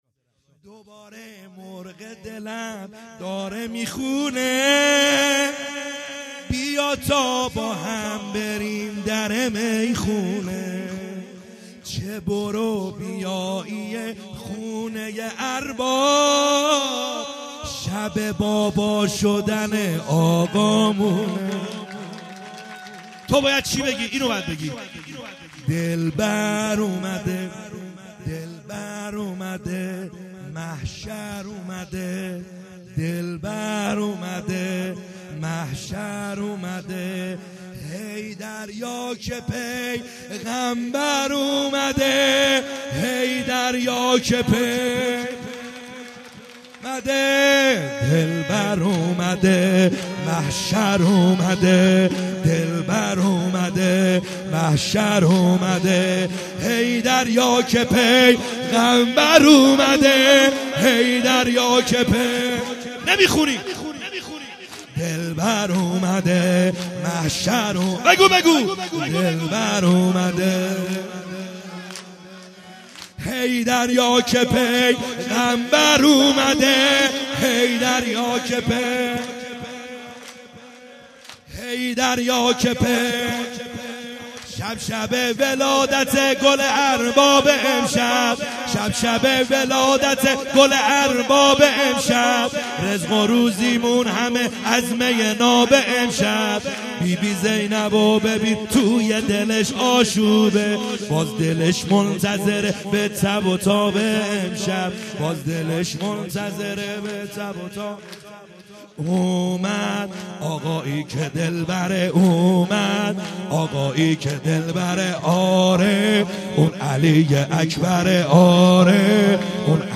خیمه گاه - بیرق معظم محبین حضرت صاحب الزمان(عج) - سرود | دوباره مرغ دلم